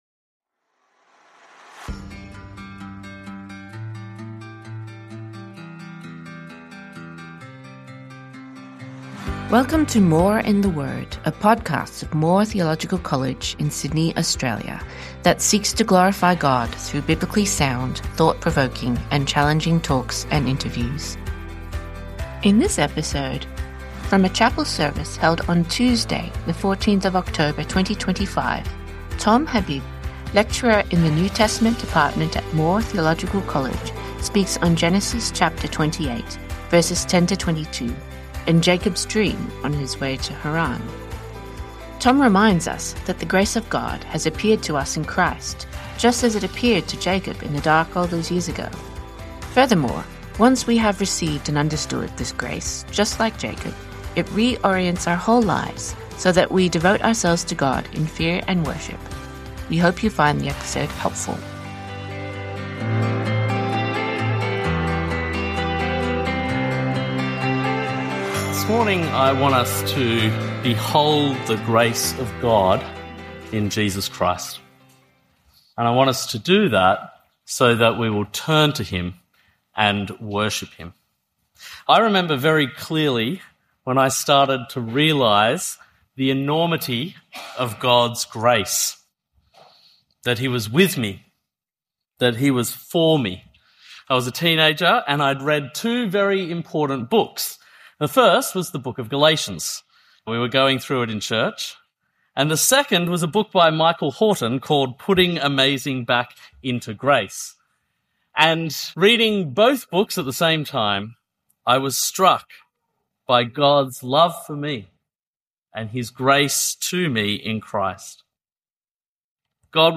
1 Compelled by the love of Christ (2 Corinthians 5:11–21) with Kanishka Raffel 24:46 Play Pause 10d ago 24:46 Play Pause Play later Play later Lists Like Liked 24:46 In this episode, from a chapel service held on Tuesday, 30 September 2025, Kanishka Raffel, Archbishop of Sydney, speaks on 2 Corinthians 5:11–21 and Paul’s declaration of his motivation for ministry.